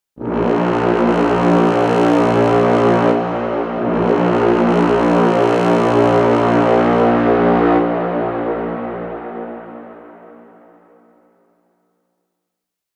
Epic Deep Braam Horn Sound Effect
Description: Epic deep braam horn sound effect.
Perfect for cinematic horror, trailers, and suspense scenes. Add intense tension and fear to your videos, games, or projects.
Epic-deep-braam-horn-sound-effect.mp3